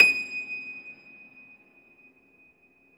53a-pno23-D5.aif